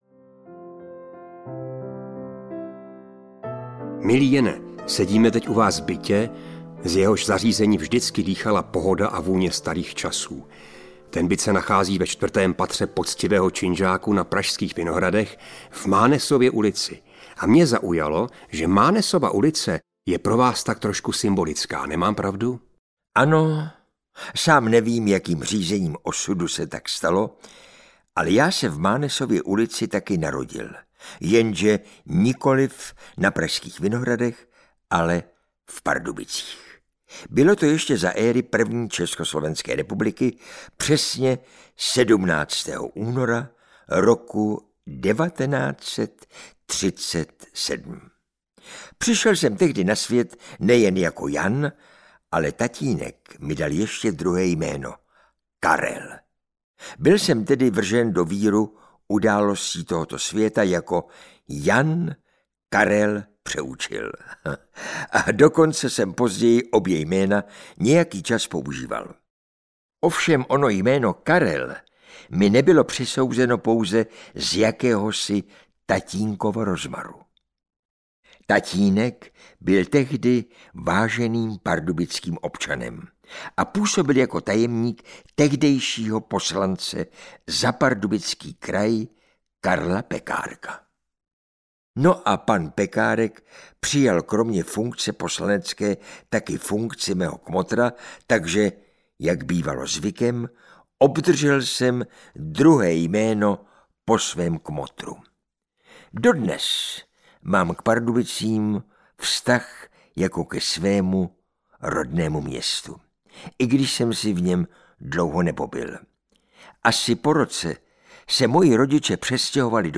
Deset klobouků Jana Přeučila audiokniha
Ukázka z knihy